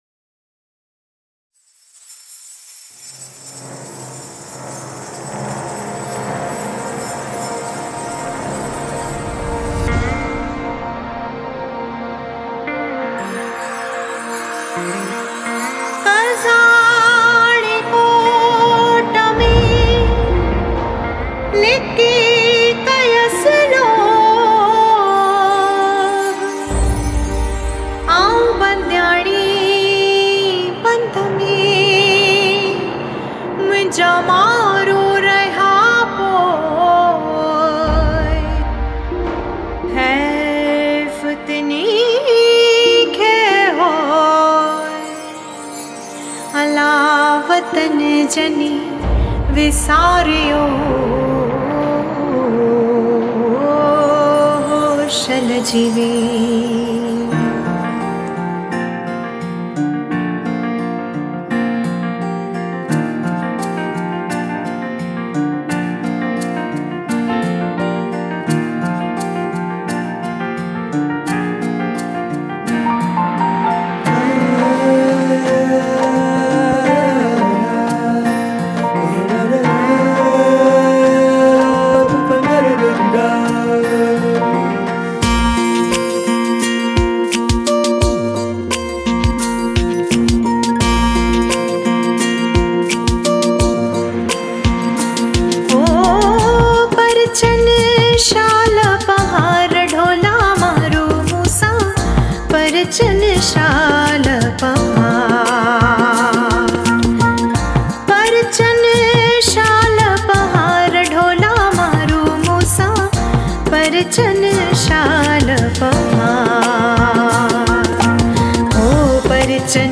Collection Melodious Sindhi SOngs